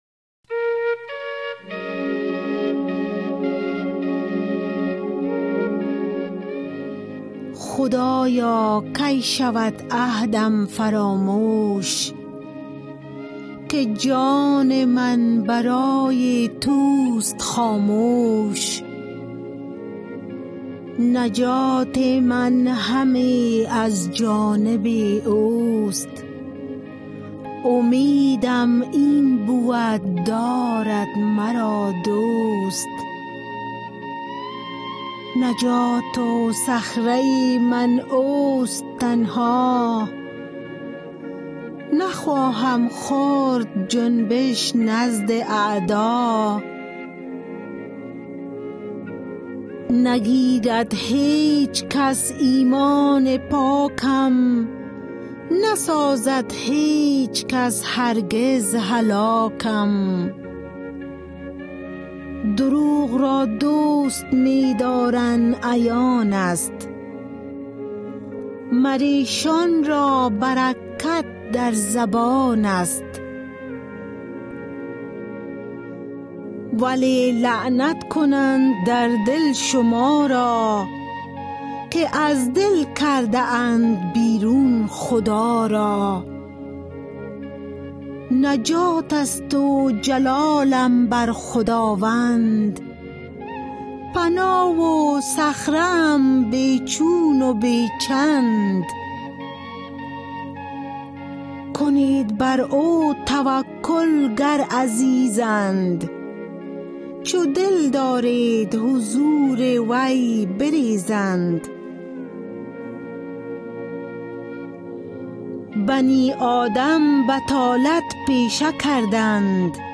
Poem Psalm 62